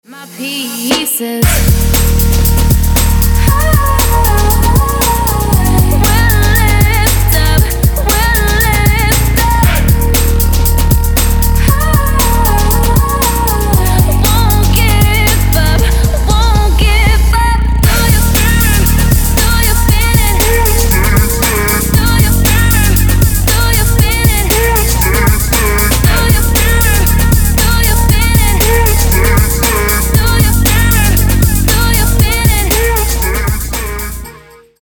• Качество: 224, Stereo
dance
Trap
club
трэп